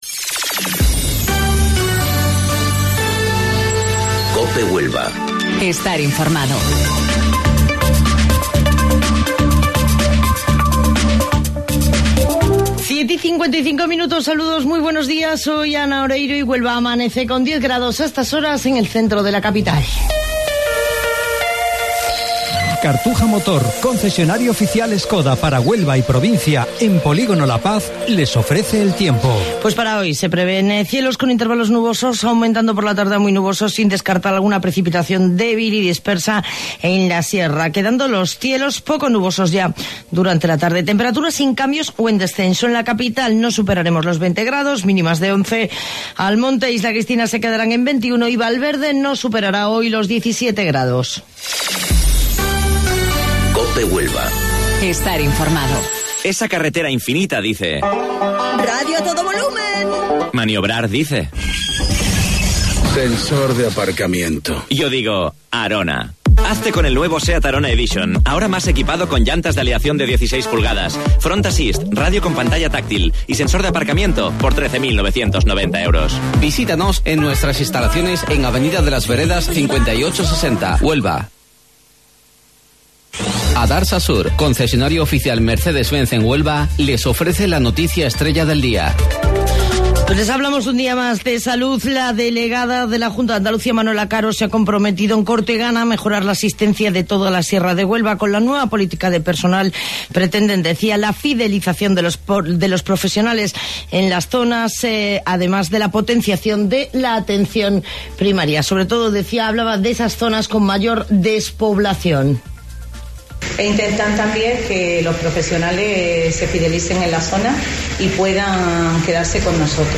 AUDIO: Informativo Local 07:55 del 9 de Abril